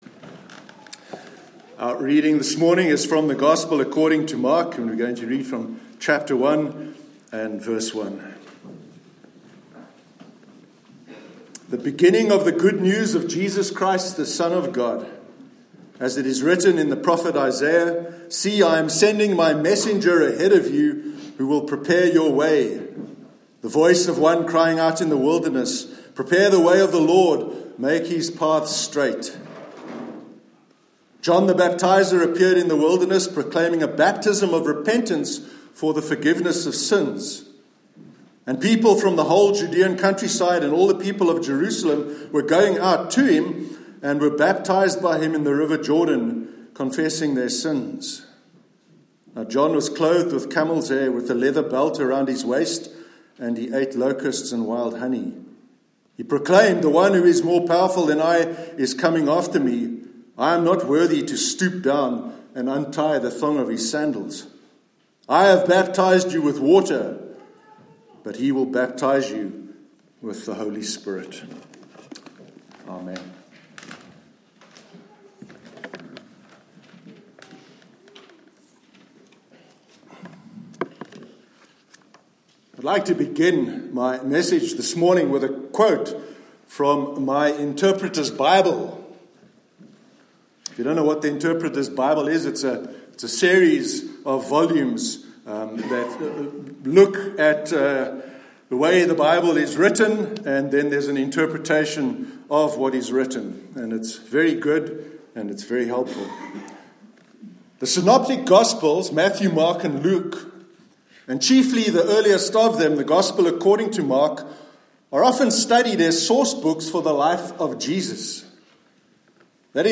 Sermon on the Opening to the Gospel of Mark- 20th January 2019